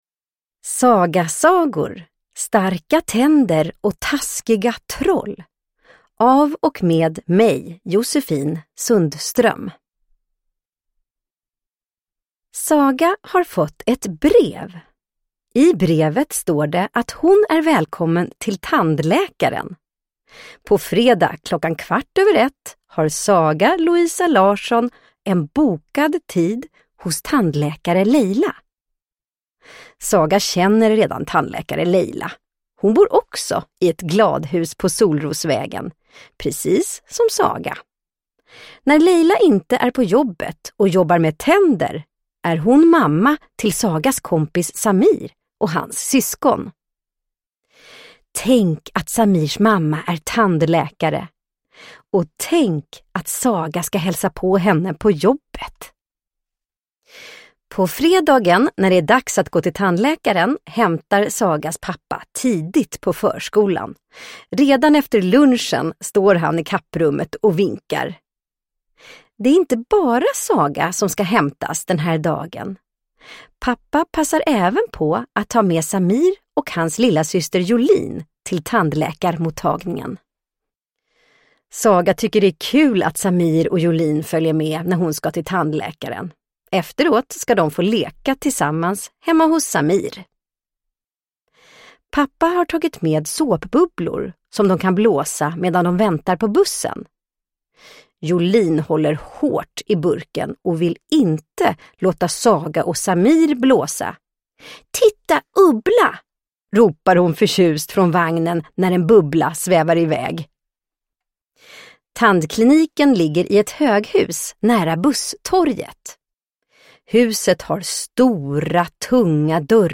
Starka tänder och taskiga troll - saga och fakta om tänder – Ljudbok – Laddas ner